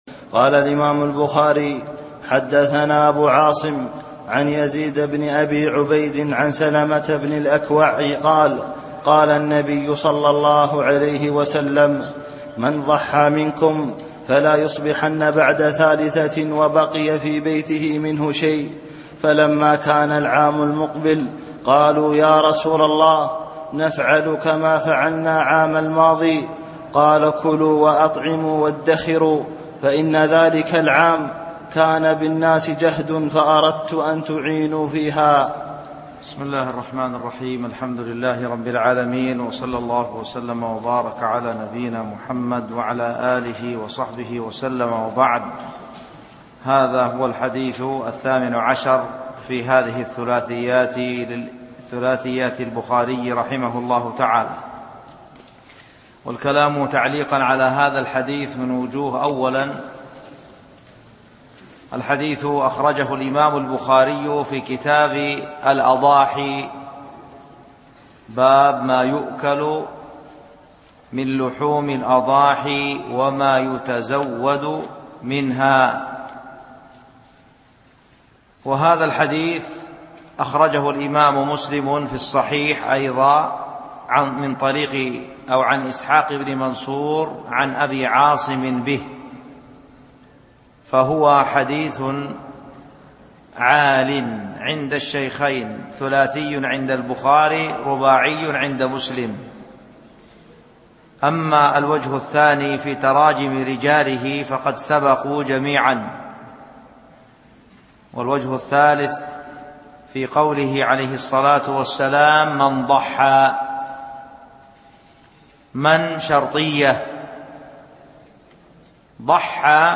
الألبوم: شبكة بينونة للعلوم الشرعية المدة: 15:41 دقائق (3.63 م.بايت) التنسيق: MP3 Mono 22kHz 32Kbps (VBR)